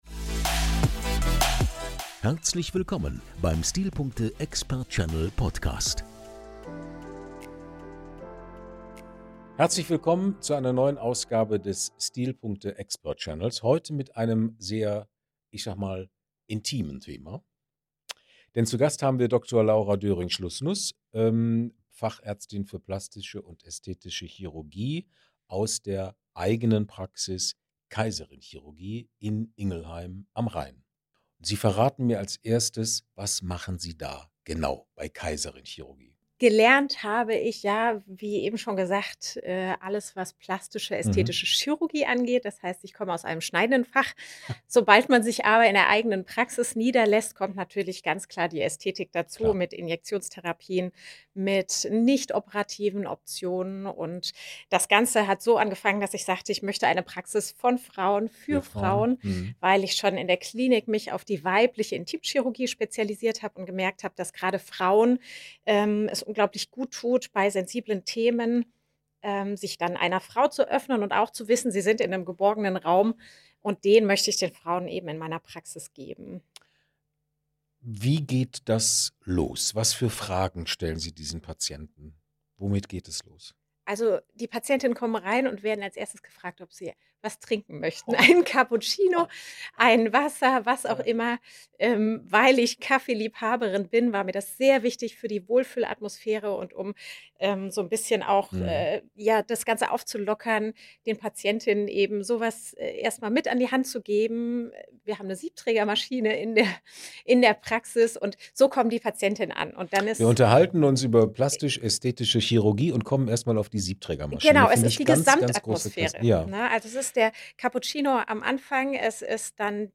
Ein Gespräch über Schönheitschirurgie, das nicht auf Äußerlichkeiten zielt, sondern auf Selbstwahrnehmung, Respekt und Vertrauen.